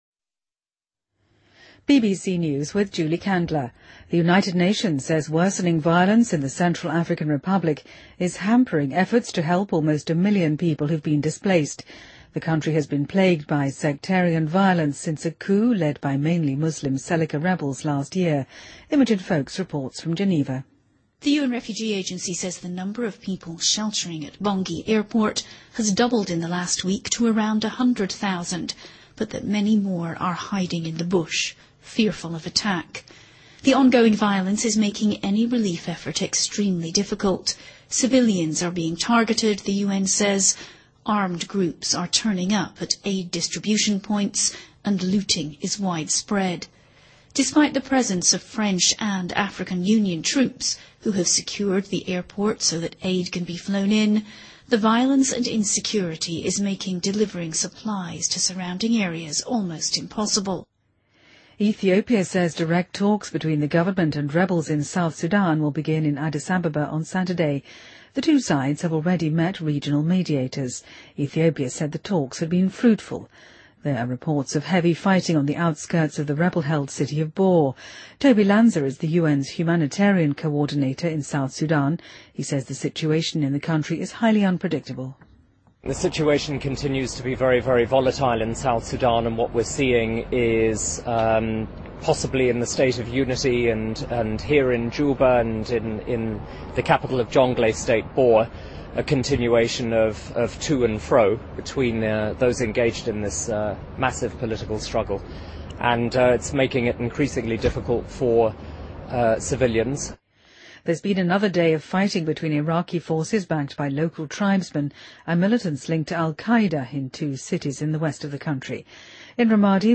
BBC news,2014-01-04